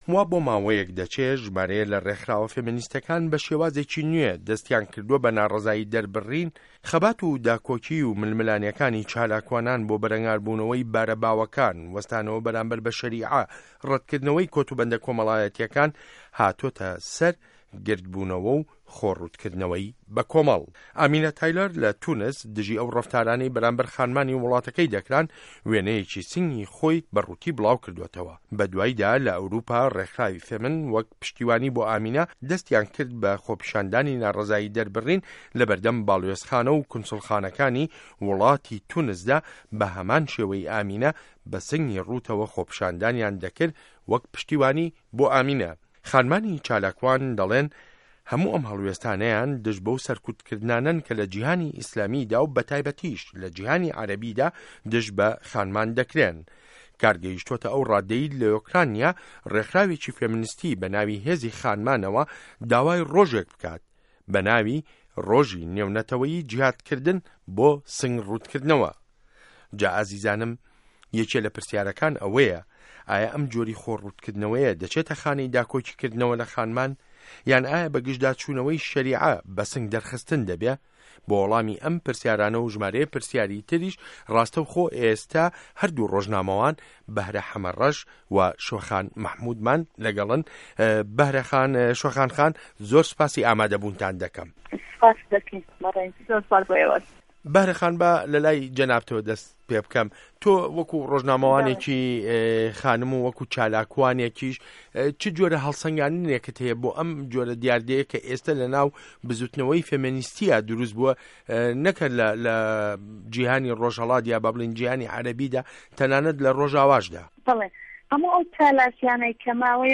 مێزگرد: خۆڕووتکردنه‌وه‌ی خانمان وه‌ک ناڕه‌زایی ده‌ربڕین